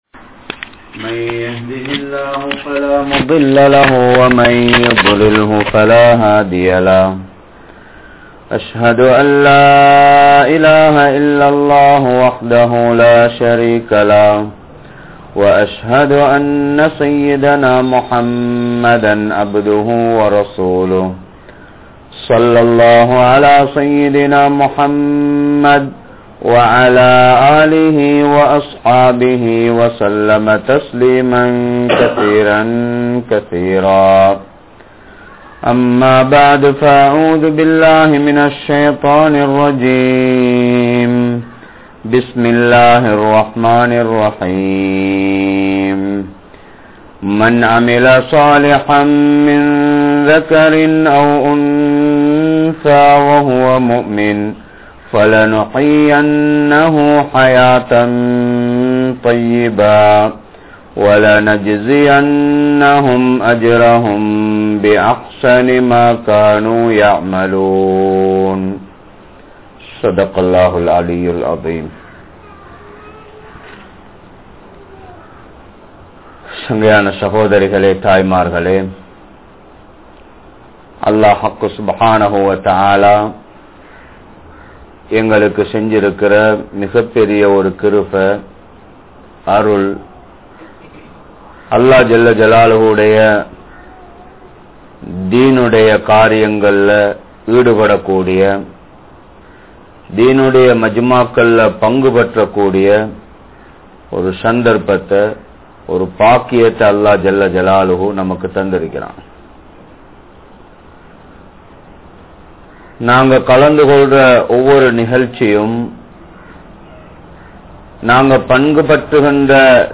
Pengalin Poruppuhal Enna? (பெண்களின் பொறுப்புகள் என்ன?) | Audio Bayans | All Ceylon Muslim Youth Community | Addalaichenai
Maruthamunai, Noor Jumua Masjith